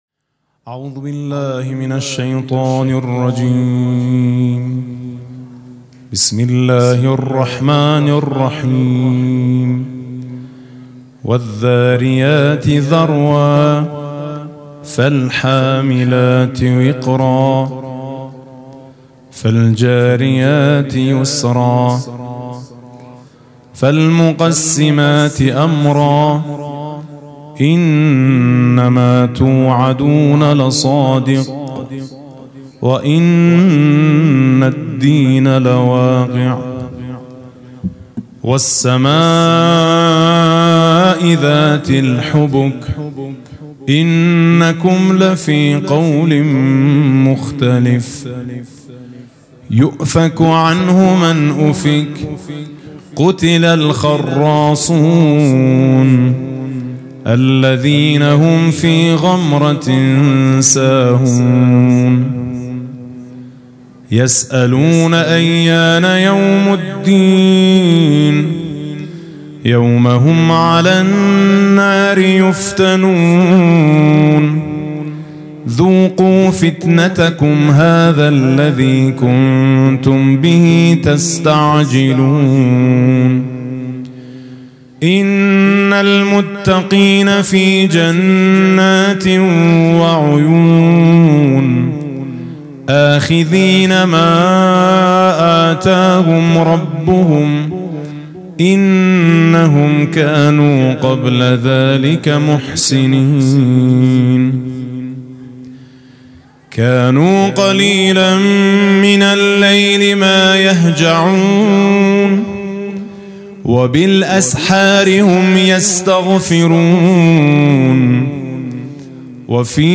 مناسبت: روضه‌ی هفتگی و قرائت زیارت امام زمان (عج)
تلاوت سوره‌ی مبارکه «ذاریات»